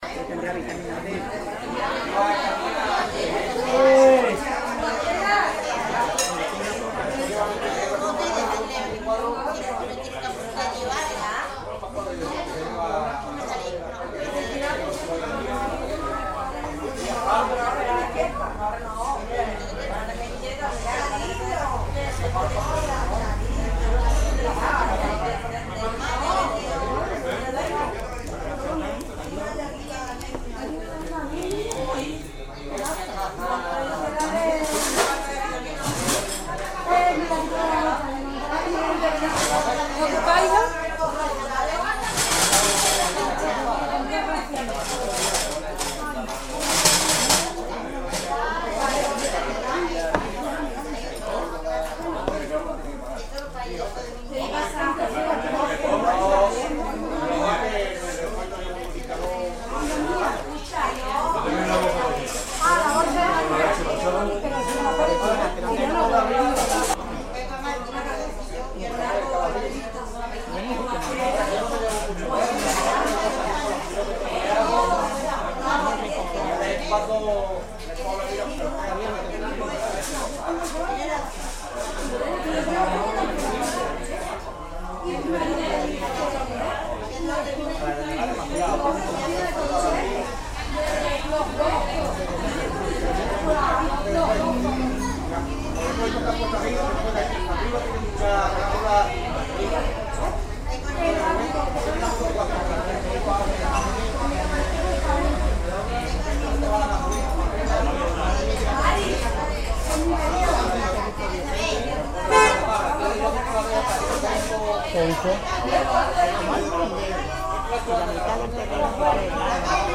Tavern
Chatter
chatter-2.ogg